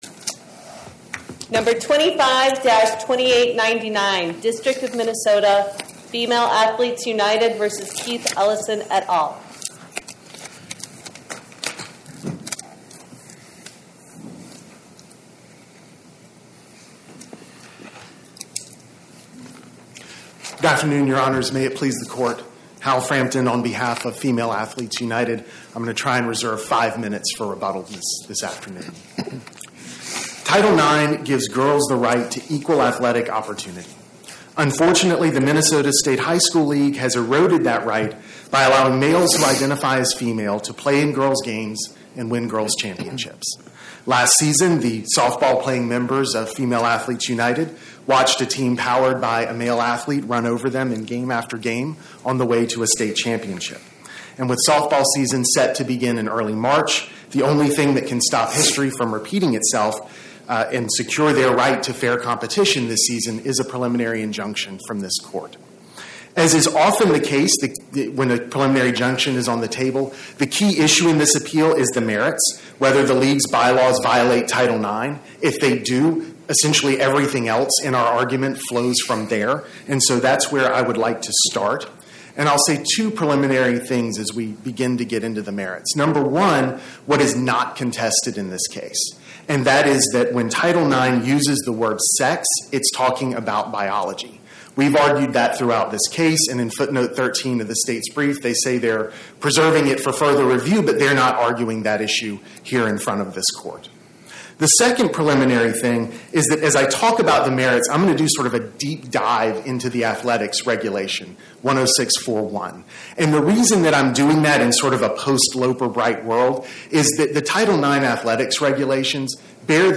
My Sentiment & Notes 25-2899: Female Athletes United vs Keith Ellison Podcast: Oral Arguments from the Eighth Circuit U.S. Court of Appeals Published On: Thu Jan 15 2026 Description: Oral argument argued before the Eighth Circuit U.S. Court of Appeals on or about 01/15/2026